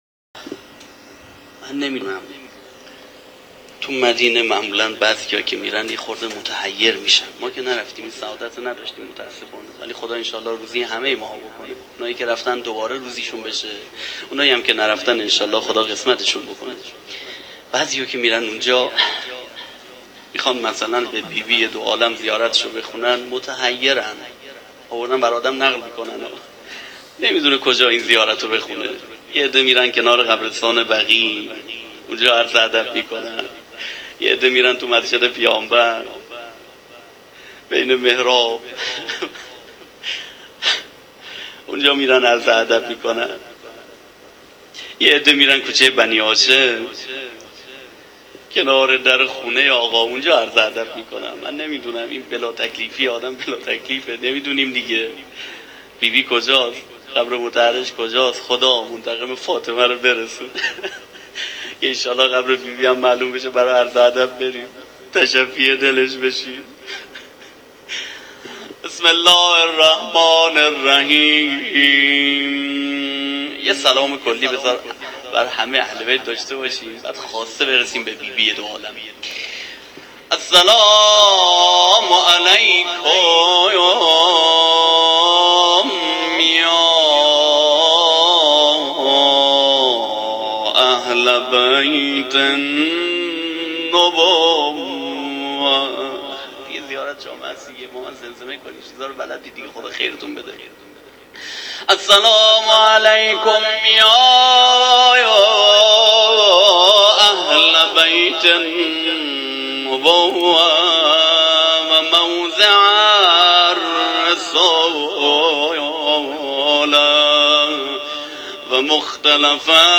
روضه شهادت حضرت زهرا